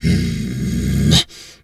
wolf_2_growl_02.wav